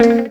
RIFFGTR 10-L.wav